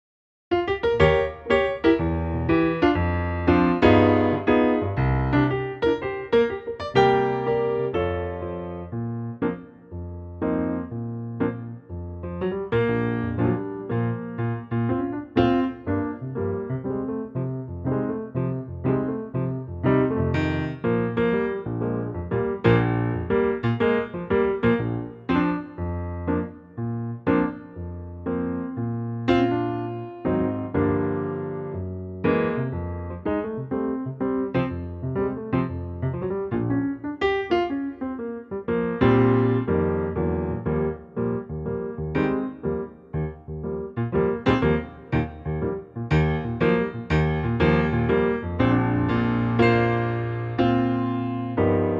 key - Bb - vocal range - F to Ab